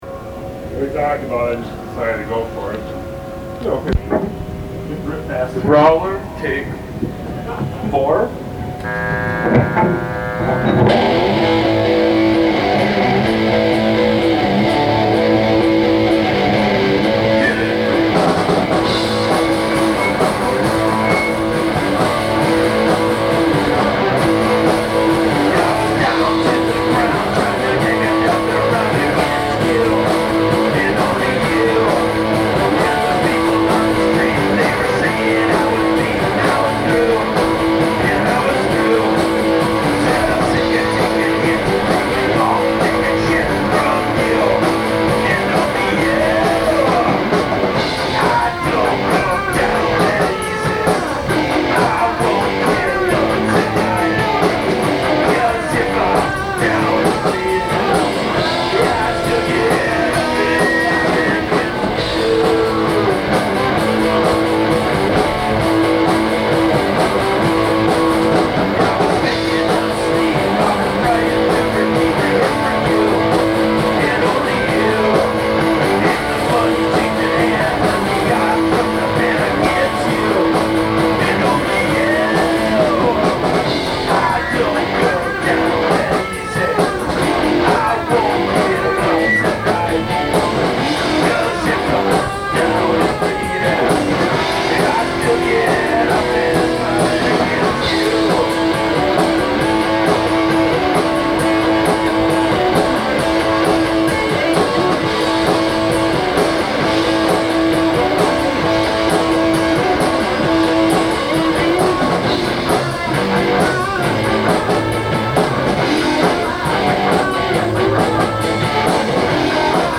recorded live during a practice